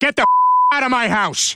Worms speechbanks
goaway.wav